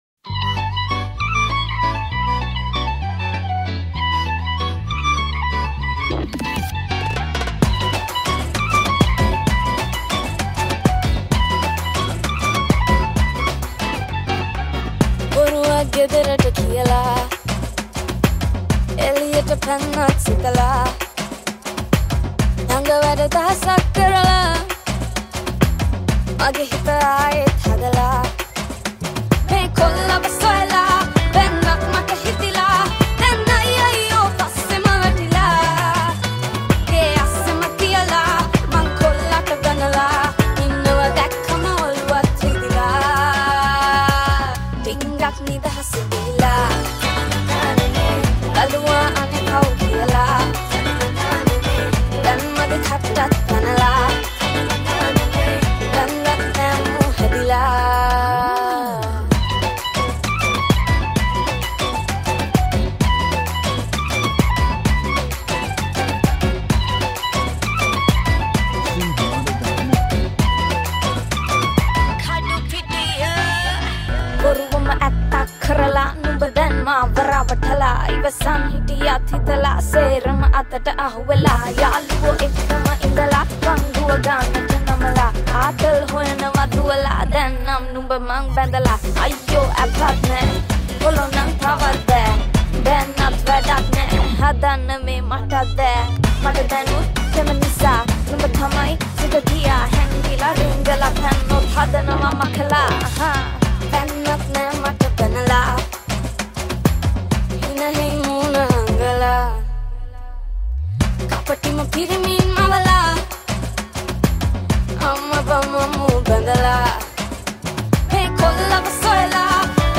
Live Bass